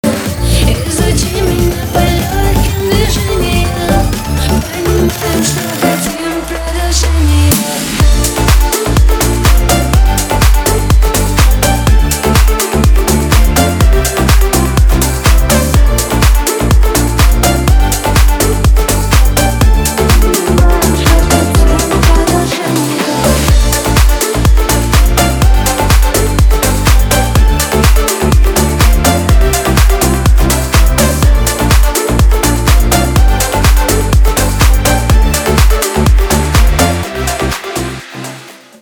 • Качество: 320, Stereo
женский вокал
dance
club
house